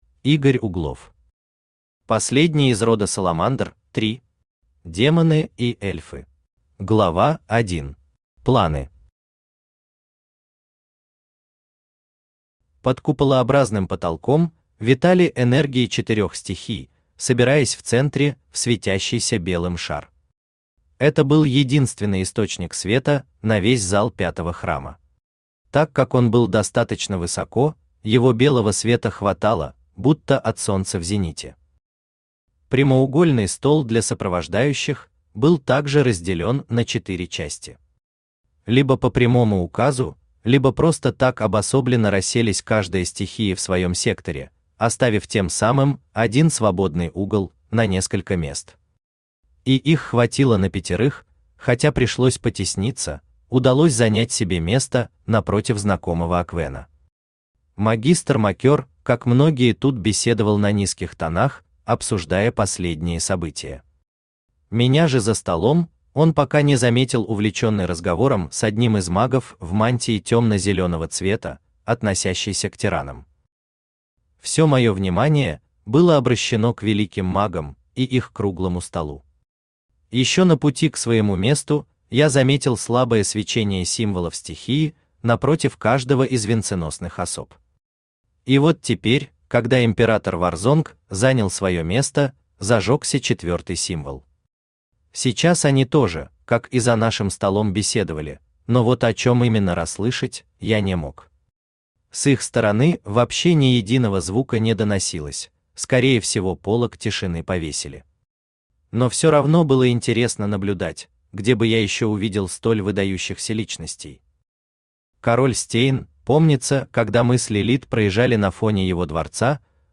Aудиокнига Последний из рода Салмандр – 3 Автор Игорь Углов Читает аудиокнигу Авточтец ЛитРес.